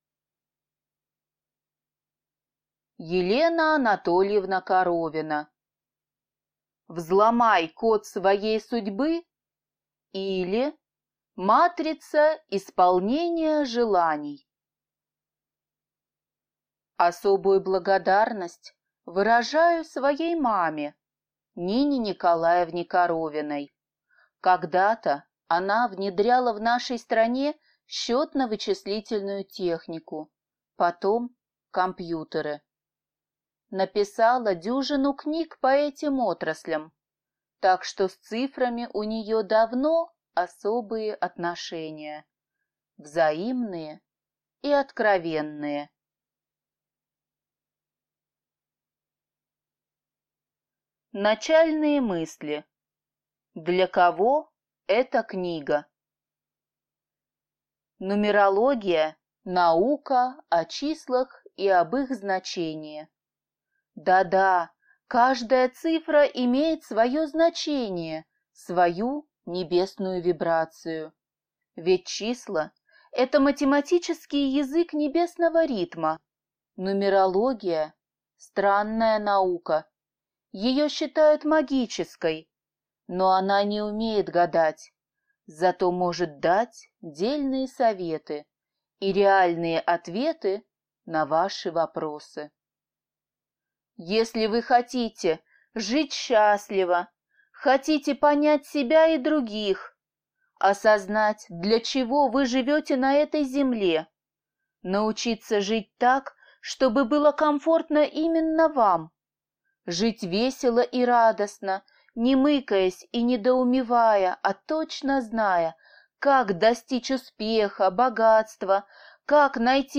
Аудиокнига Взломай код своей судьбы, или Матрица исполнения желаний | Библиотека аудиокниг